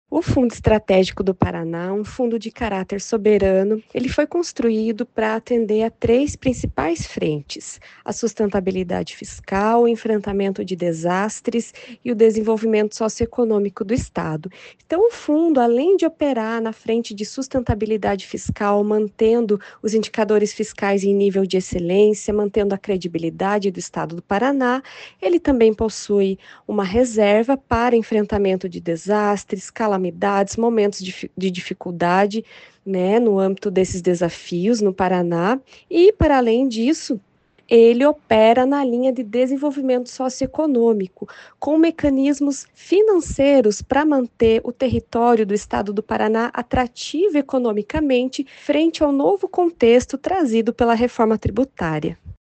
Sonora da diretora do Tesouro Estadual, Carin Deda, sobre o projeto de lei que cria Fundo Estratégico do Paraná